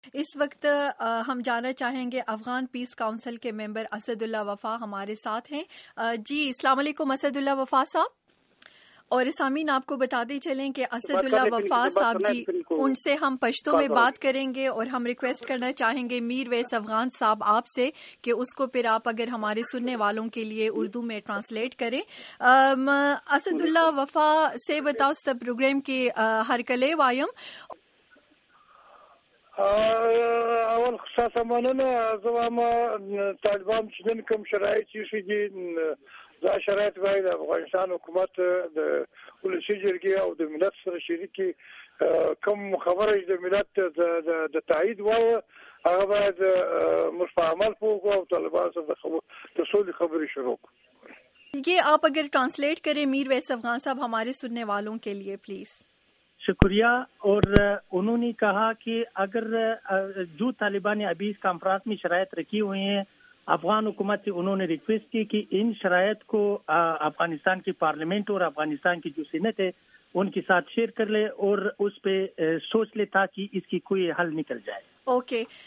افغان امن کونسل کے رکن، اسد اللہ وفا کی بات چیت